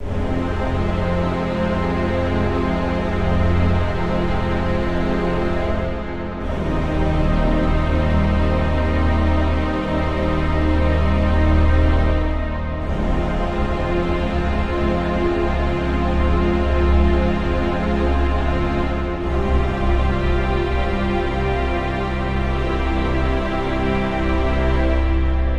电影音乐剪辑
Tag: 弦乐 小提琴 合成器 处理 音乐片 电影 电影 行动迟缓 氛围